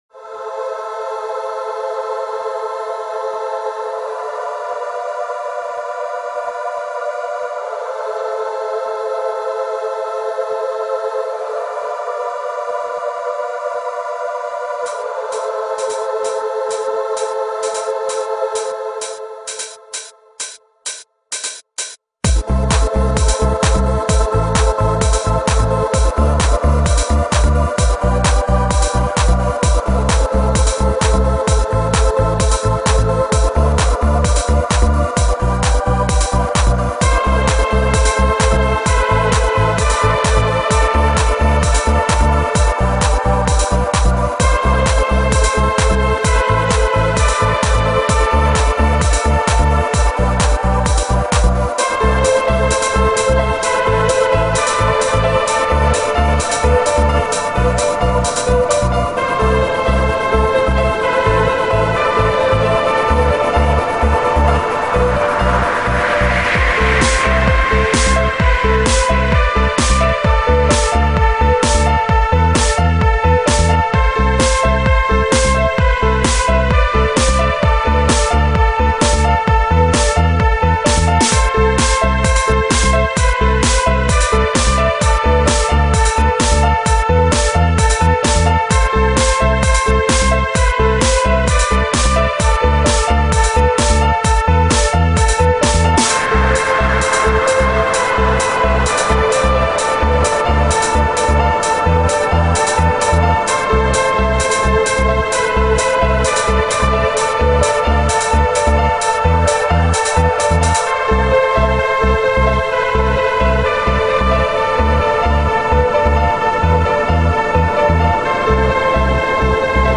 Remember im still new to music D: It even Loops!